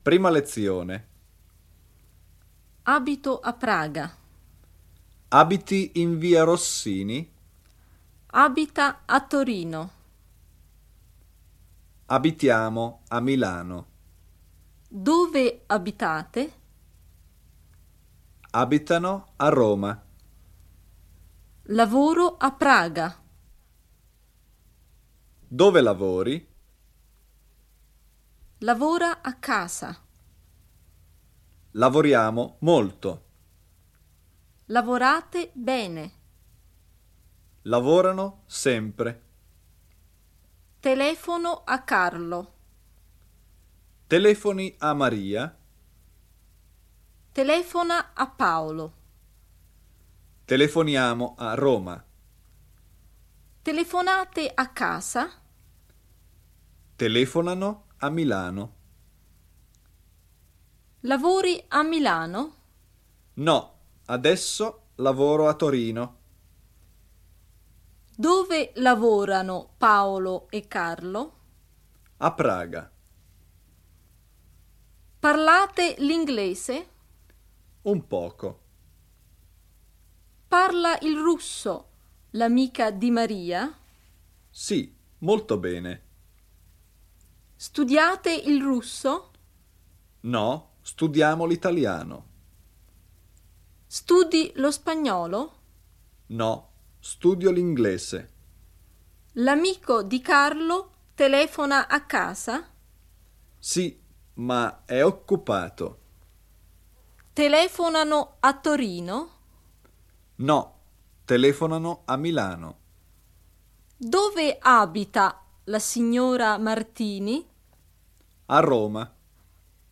Vyberte Audiokniha 69 Kč Další informace